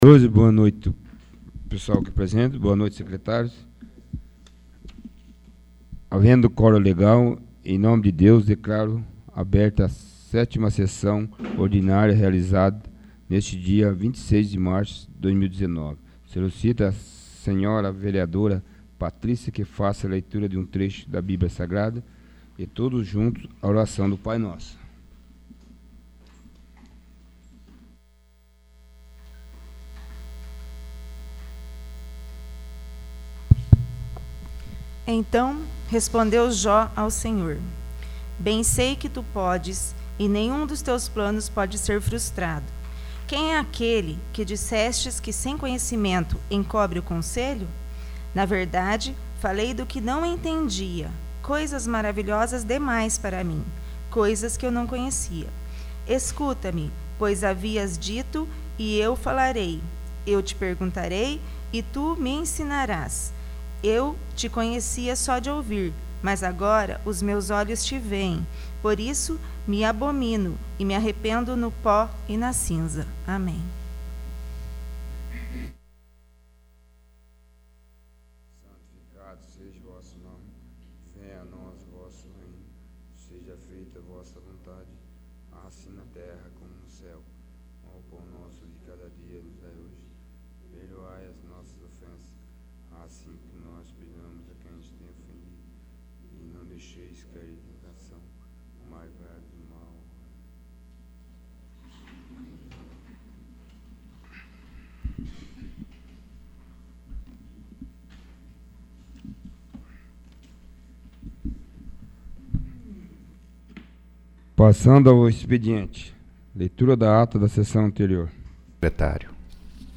7º. Sessão Ordinária